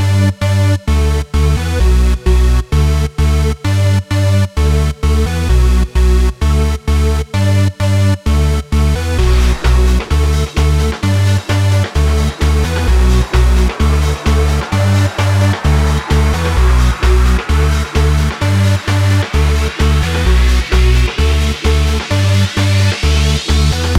no Backing Vocals Dance 3:14 Buy £1.50